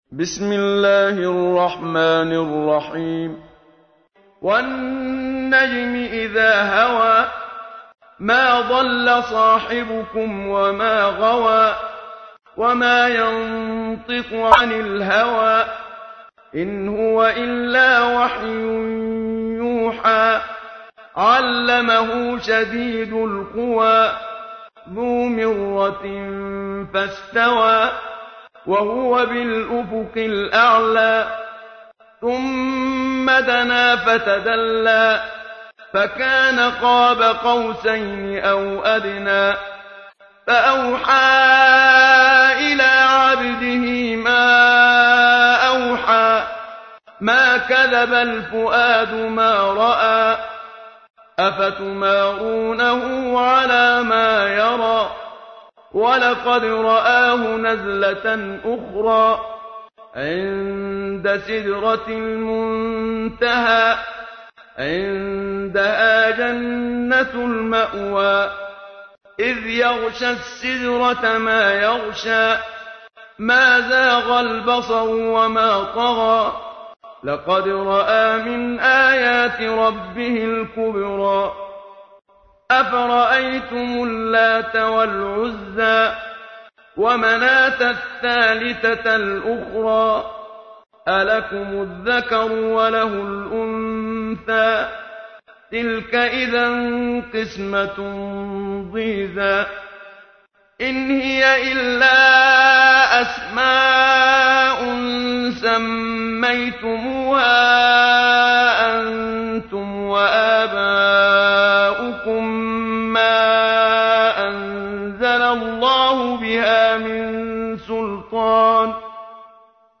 تحميل : 53. سورة النجم / القارئ محمد صديق المنشاوي / القرآن الكريم / موقع يا حسين